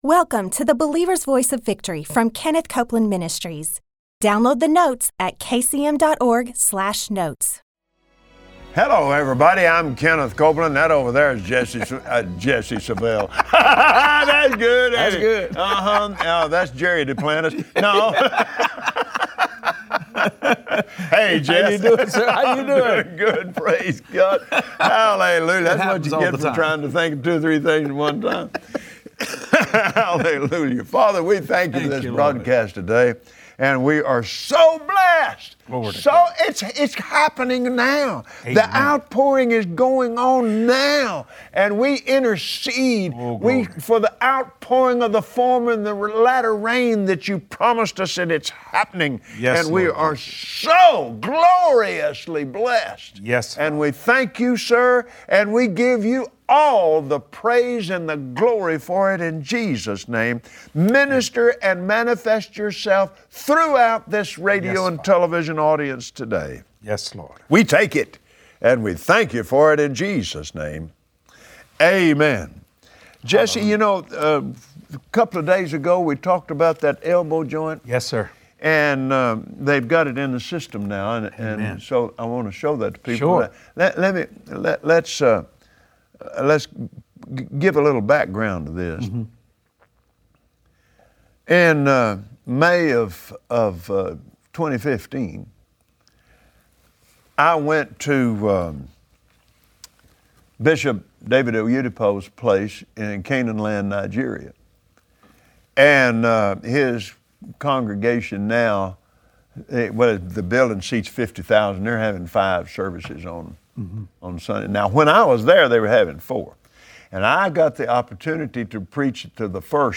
Join Kenneth Copeland and his guest, Jesse Duplantis, as they show you how to use that authority.